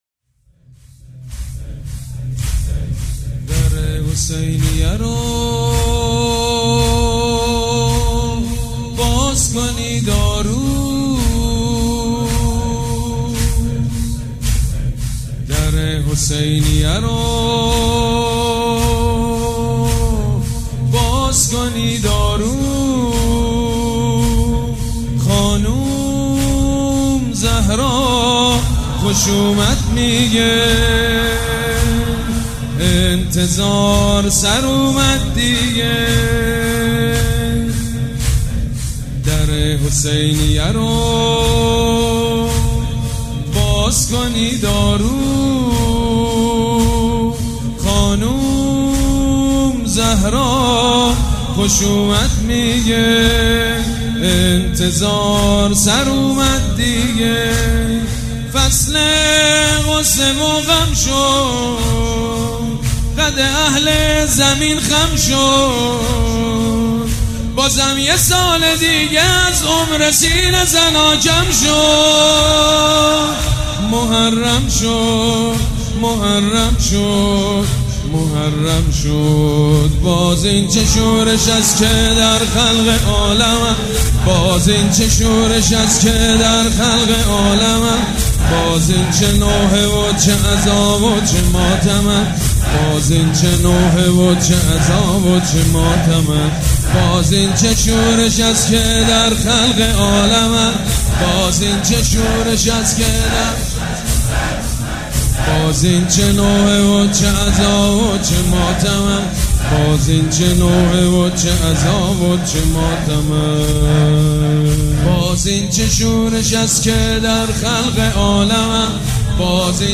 مرثیه سرایی